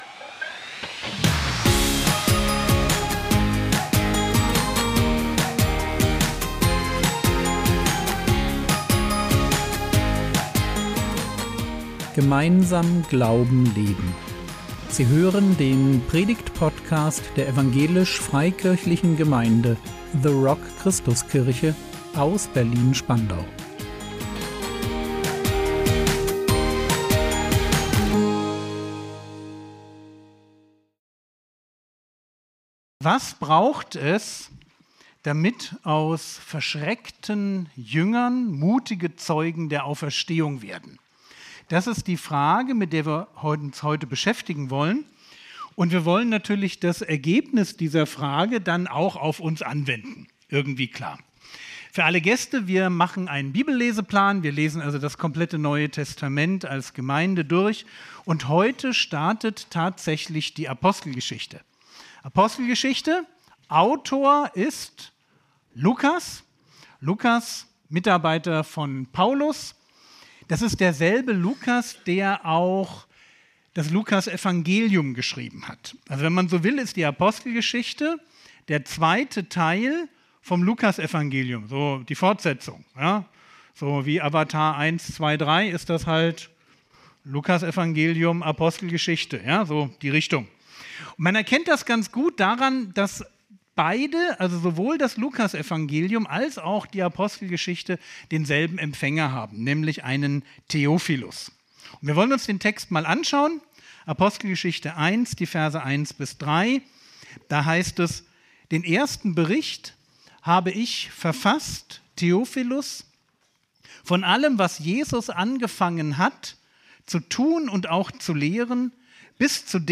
Mutig evangelisieren | 15.02.2026 ~ Predigt Podcast der EFG The Rock Christuskirche Berlin Podcast